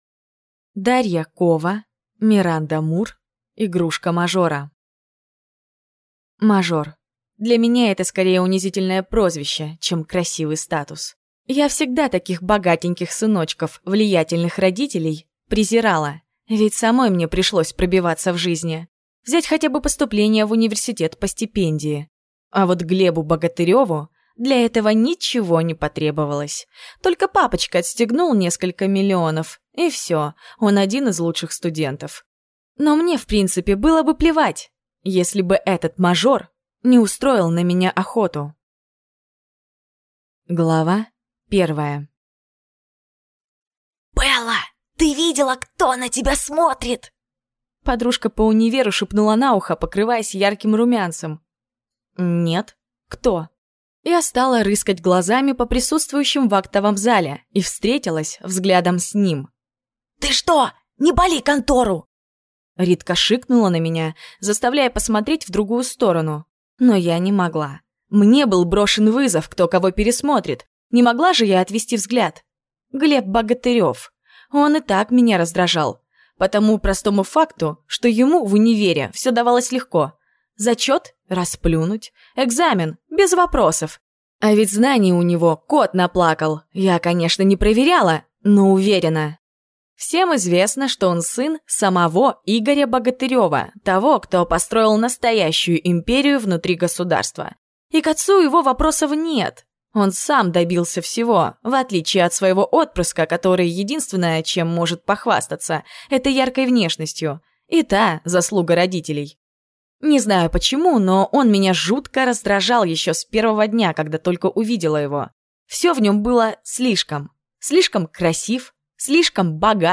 Аудиокнига Игрушка мажора | Библиотека аудиокниг
Прослушать и бесплатно скачать фрагмент аудиокниги